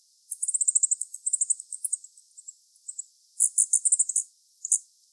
Calypte_costae.mp3